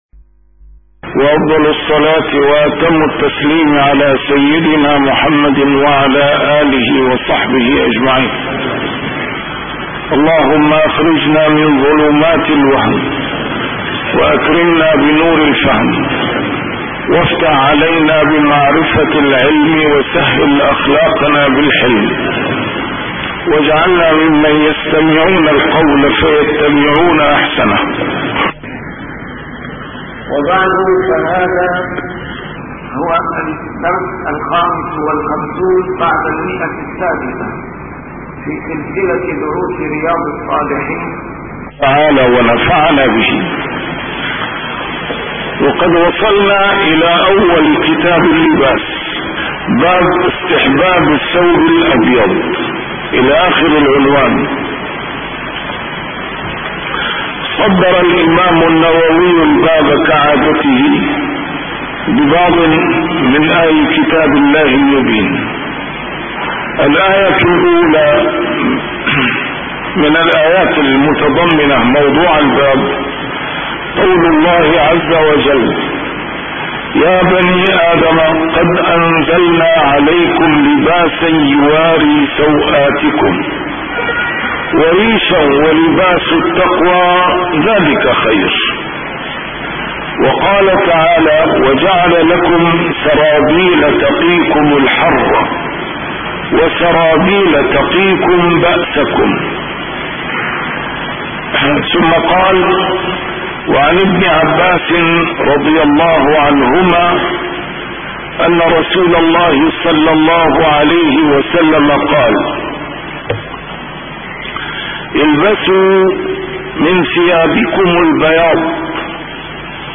شرح كتاب رياض الصالحين - A MARTYR SCHOLAR: IMAM MUHAMMAD SAEED RAMADAN AL-BOUTI - الدروس العلمية - علوم الحديث الشريف - 655- شرح رياض الصالحين: استحباب الثوب الأبيض